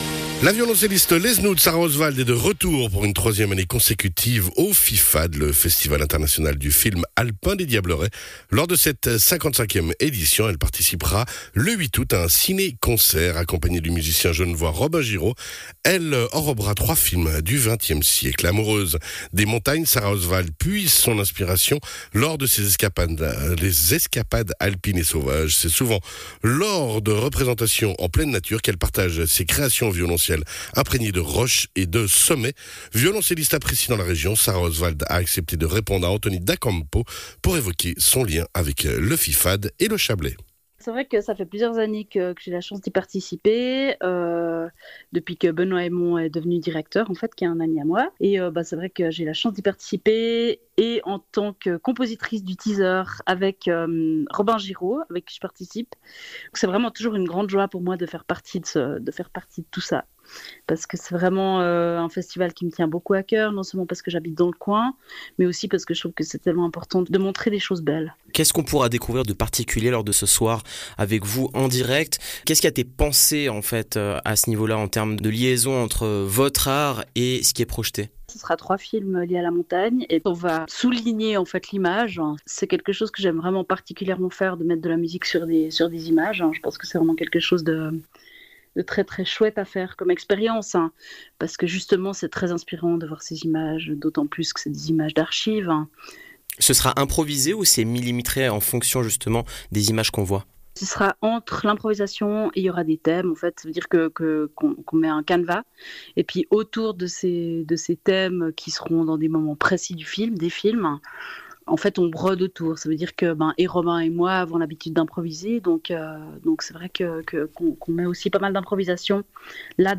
a accepté de répondre à nos questions